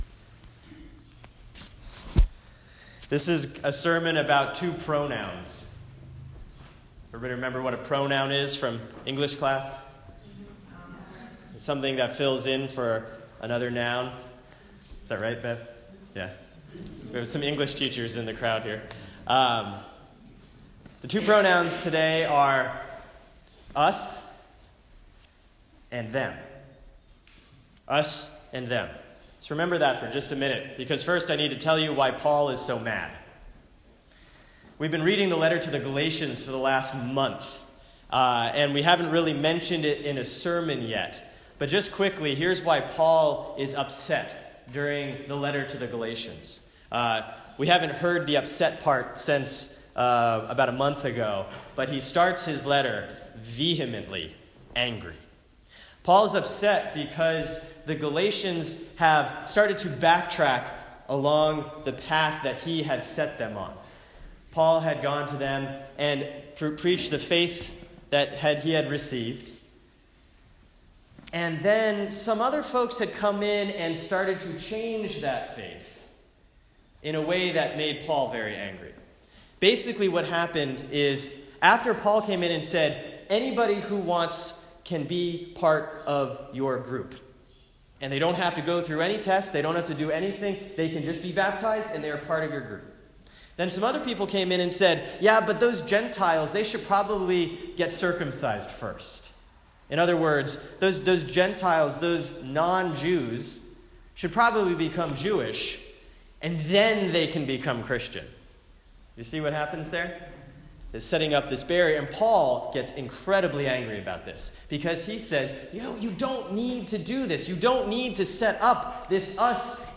sermon-6-19-16.m4a